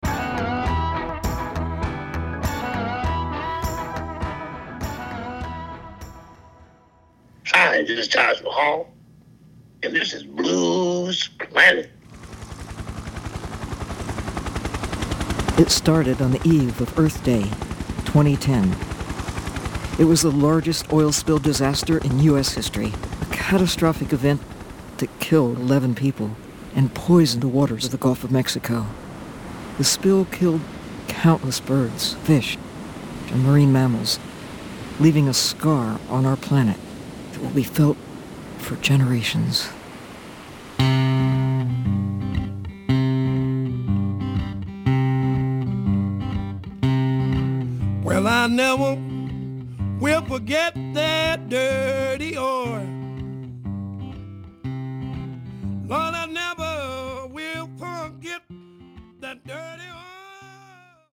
Blues Planet: Sounds (Audiobook)
Written by: Wyland Read by: Dr. Sylvia Earle Duration:
Blues-Planet-Audio-Book_SAMPLE.mp3